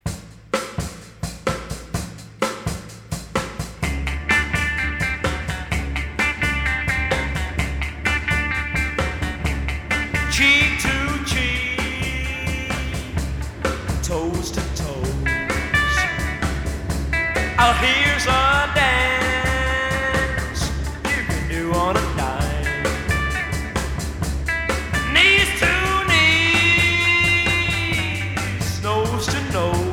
R'n'r Deuxième EP retour à l'accueil